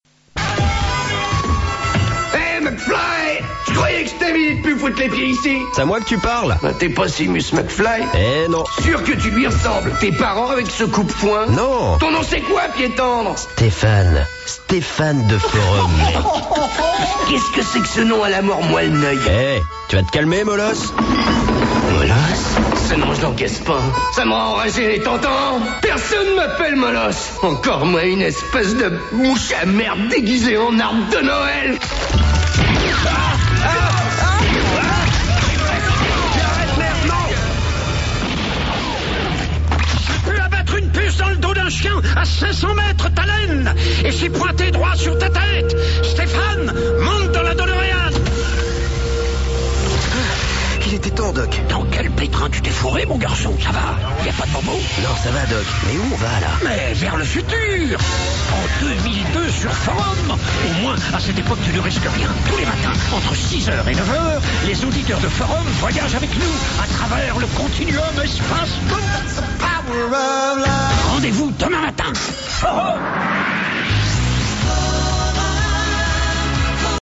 Parodies à la radio
Parodie Retour vers le futur sur la radio Forum